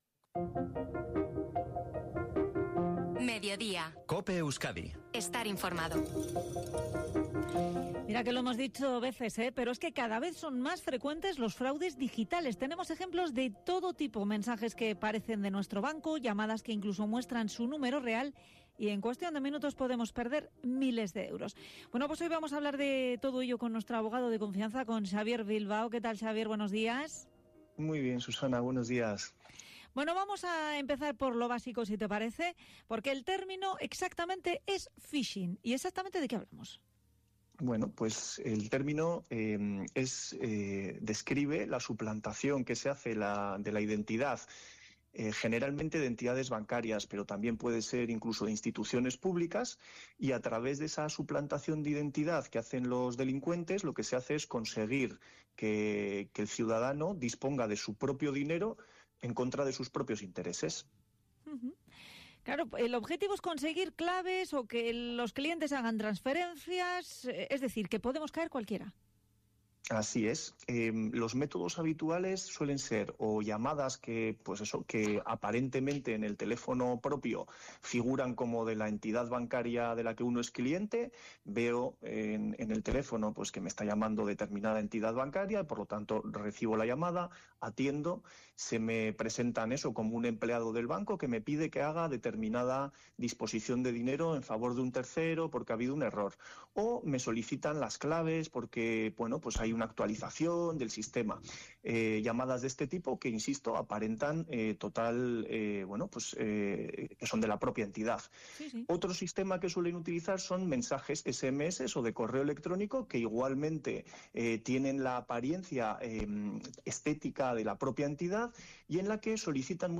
ENTREVISTA EN COPE SOBRE PHISHING - Despacho Abogados San Jose